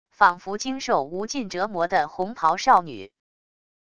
仿佛经受无尽折磨的红袍少女wav音频